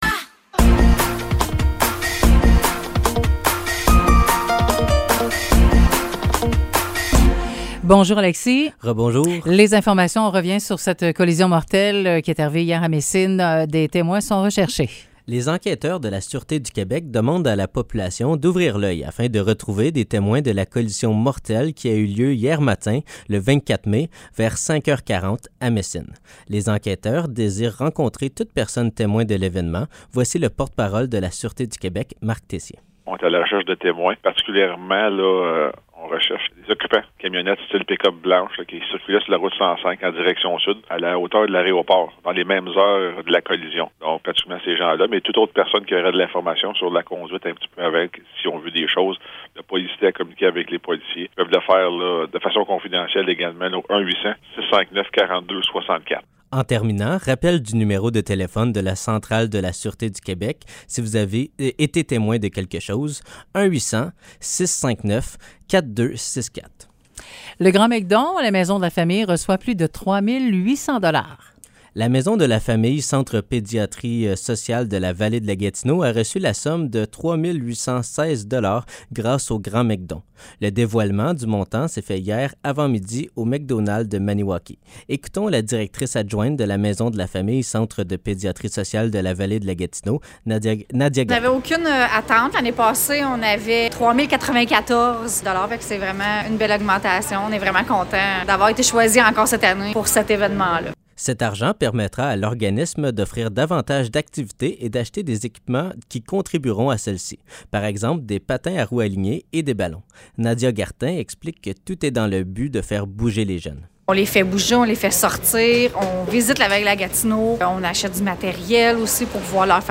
Nouvelles locales - 25 mai 2023 - 10 h